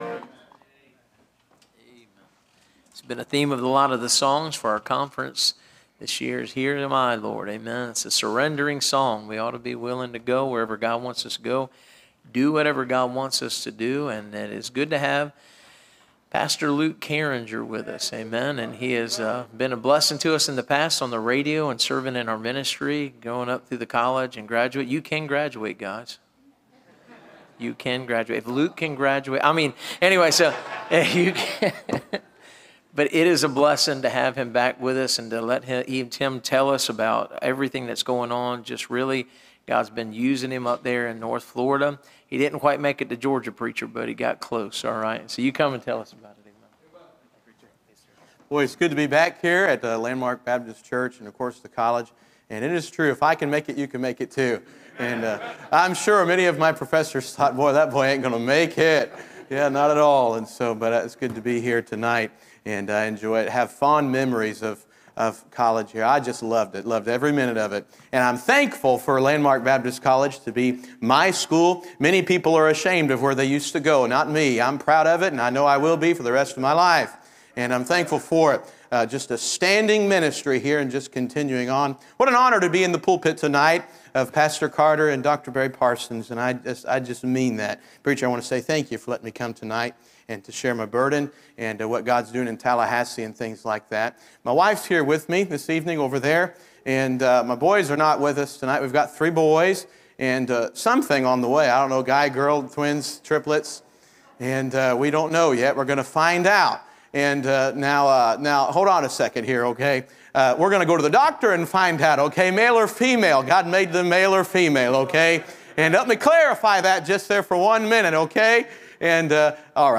Series: (Missions Conference 2025)